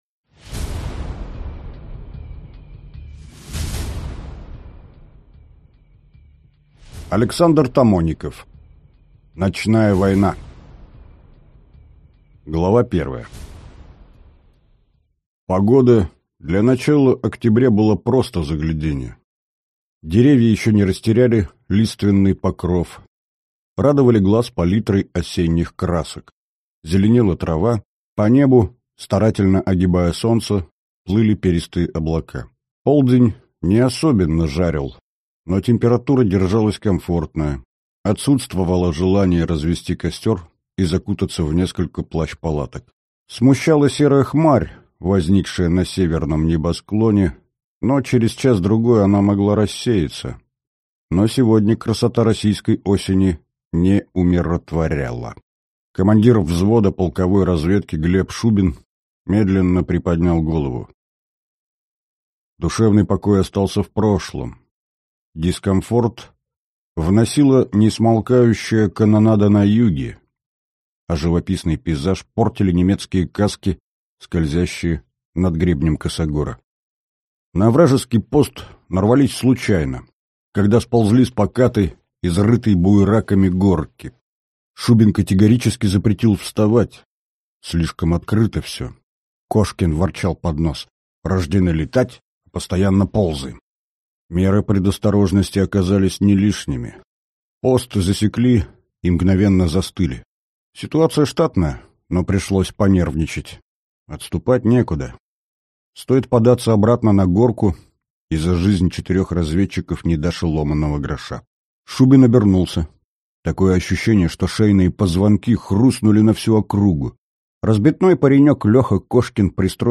Аудиокнига Ночная война | Библиотека аудиокниг